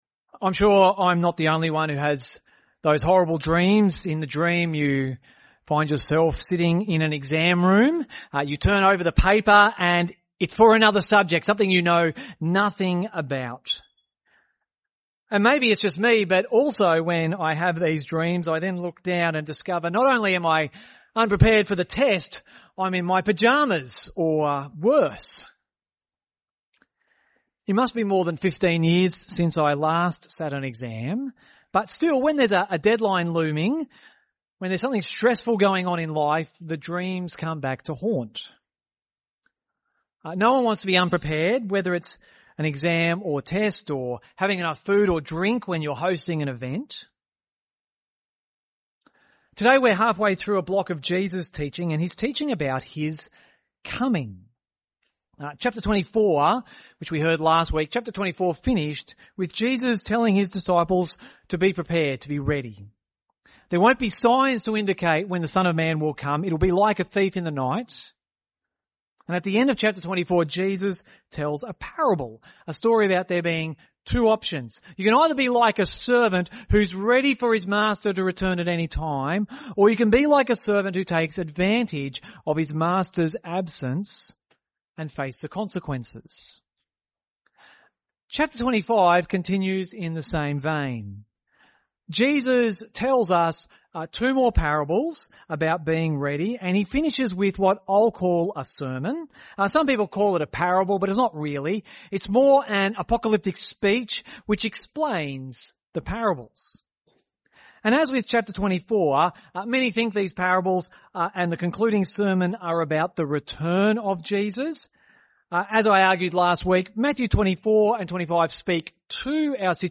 Gympie Presbyterian Church